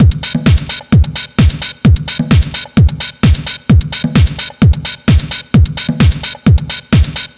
NRG 4 On The Floor 008.wav